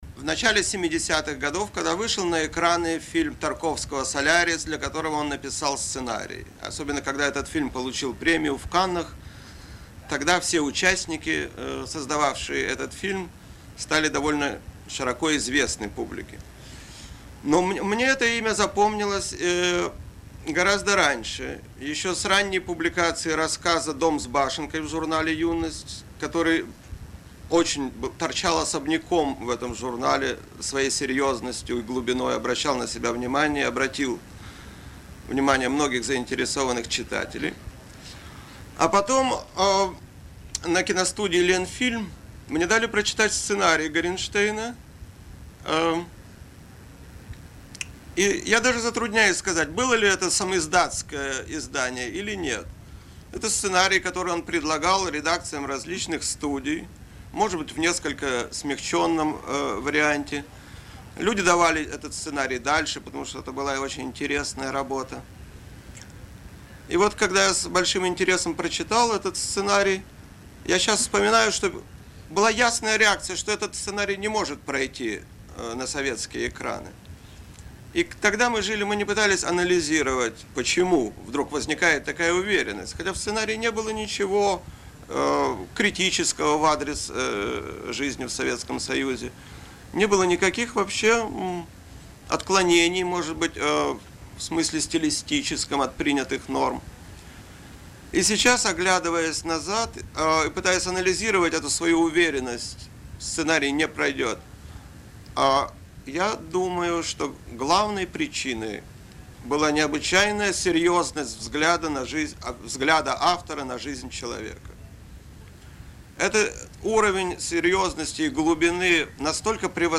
Фридрих Горенштейн в беседе